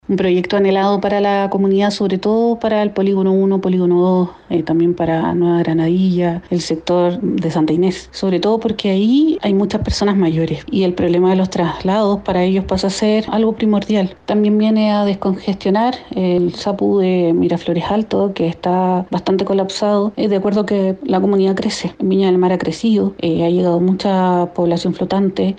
Así lo expresó la Concejala Nancy Díaz.